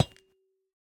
Minecraft Version Minecraft Version snapshot Latest Release | Latest Snapshot snapshot / assets / minecraft / sounds / block / copper / step5.ogg Compare With Compare With Latest Release | Latest Snapshot